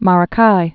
(märə-kī)